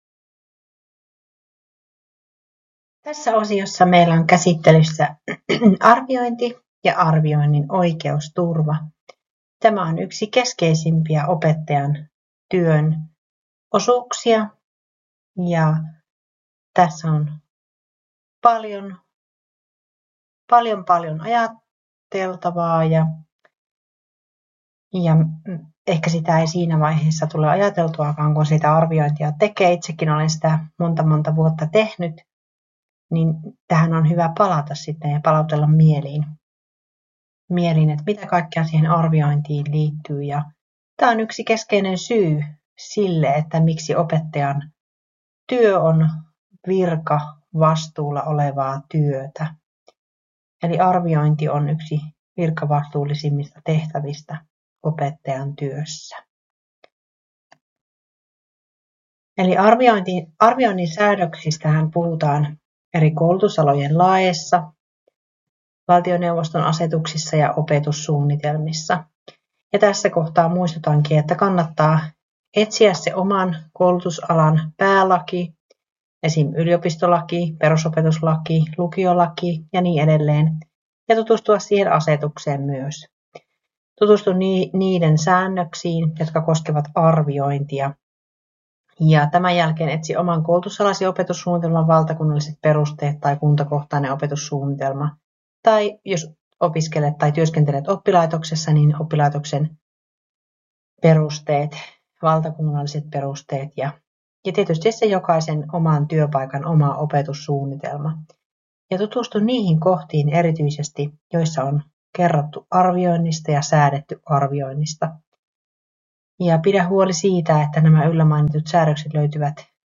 KTK0006 Opetushallinnon luentotallenne